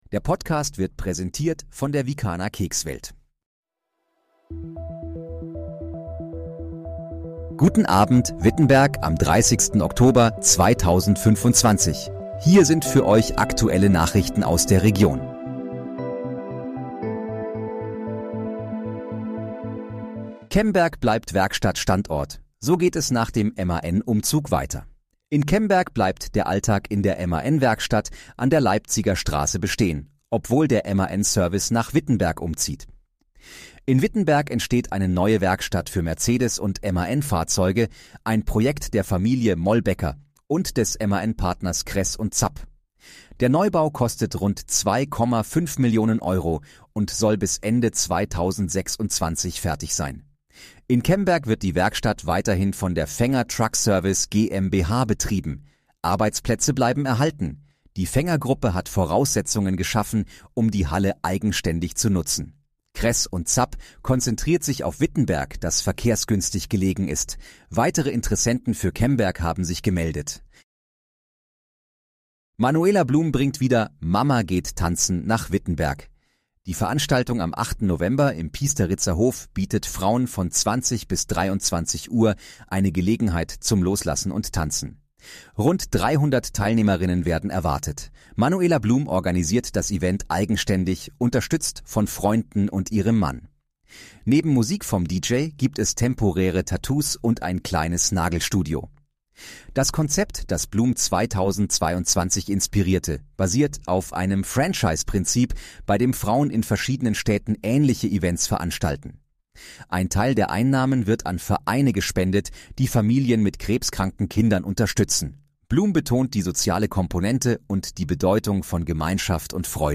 Guten Abend, Wittenberg: Aktuelle Nachrichten vom 30.10.2025, erstellt mit KI-Unterstützung
Nachrichten